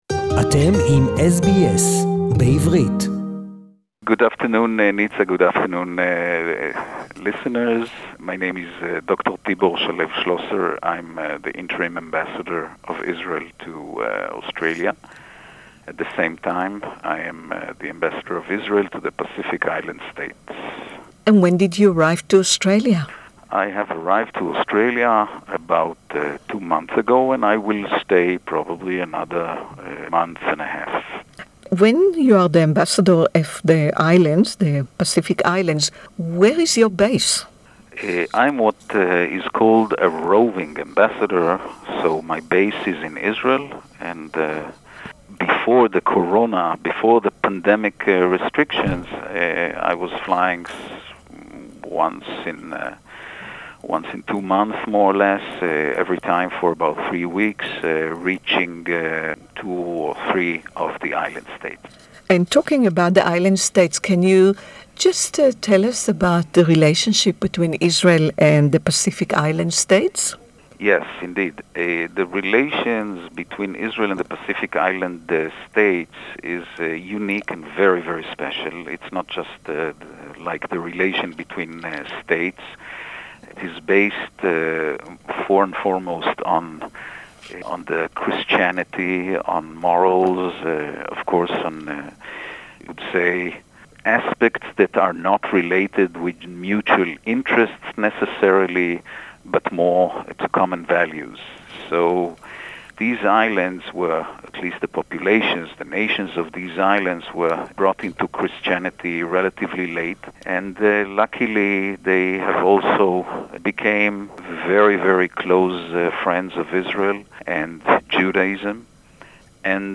A special ceremony was held last week in Brisbane to recognize a Dutch family who saved a Jewish child during the Holocaust. We spoke to Dr Tibor Shalev Schlosser the interim ambassador of Israel and the Ambassador of Israel to the Pacific Island states, who represented Israel and Yad Vashem at the ceremony.